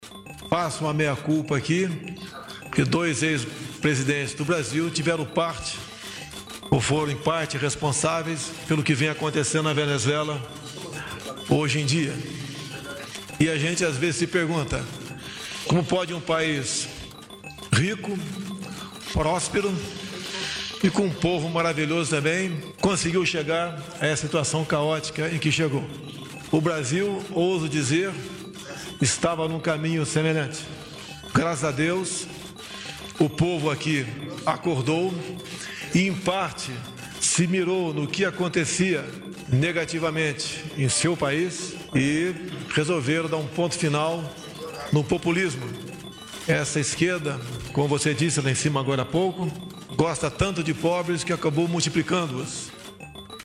Após o encontro, Bolsonaro se pronunciou fazendo alusão aos governos anteriores.